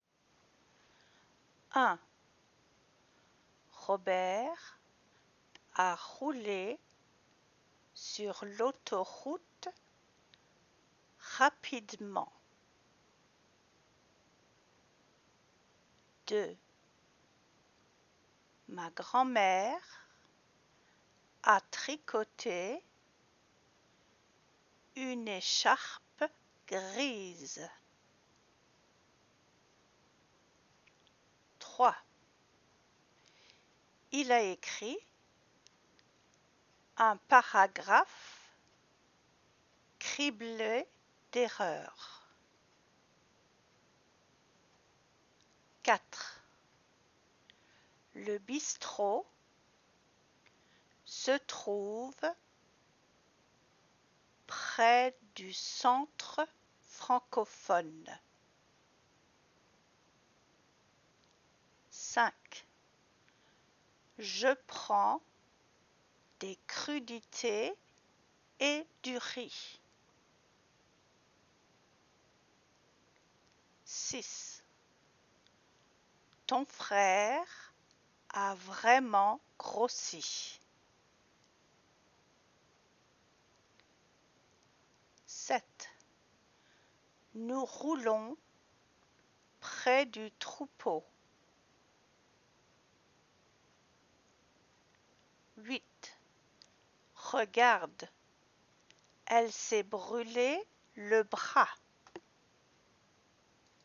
Pronunciation – The Letter ‘r’
Lisez à haute voix (read aloud) ces phrases et ensuite écoutez leurs prononciations.